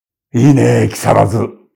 アクアコイン決済音にご協力いただきました
電子地域通貨「アクアコイン」の運用開始5周年を記念し、アクアコイン決済音5周年記念特別バーションにご協力いただきました。
特別決済音「いいねぇ、木更津」 (音声ファイル: 49.6KB)